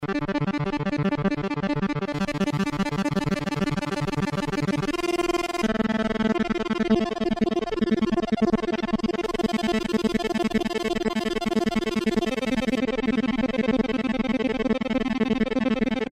demo HEAR arpeggiator